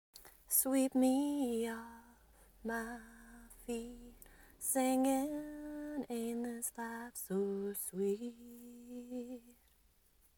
You sang it well..